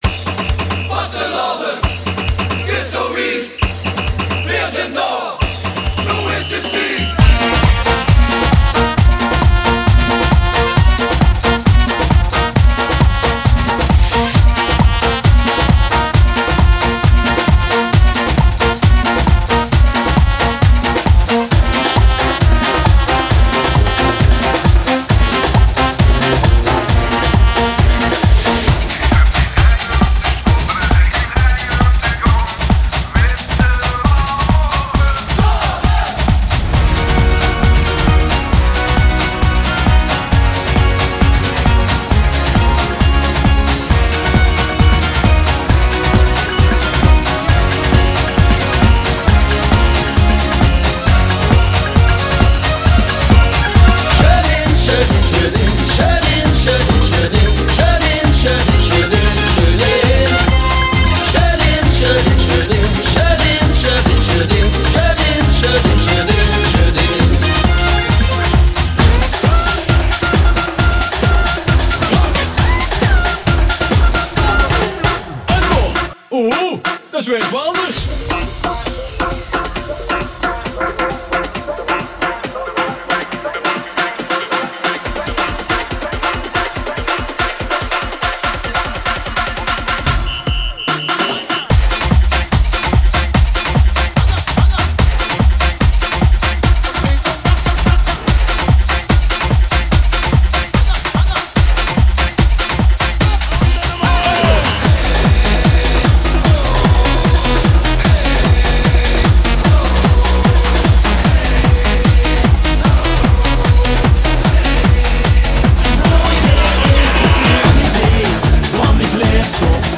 FEBRUARE 2025 • CARNAVAL/FEESt • 6,5 MIN